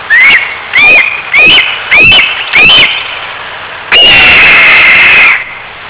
Las vocalizaciones de llamado corresponden a sonidos breves de estructura acústica simple, sin un patrón definido y compuesto de no mas de dos sílabas.
Vocalización de un Peuco